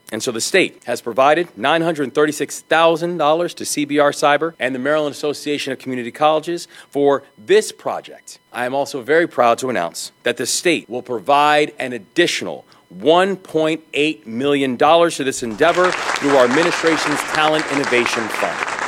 The funding will be used by the state’s community colleges to offer training through interactive simulated platforms that provide hands-on learning.  Governor Moore made the announcement at Howard Community College…